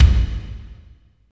35.06 KICK.wav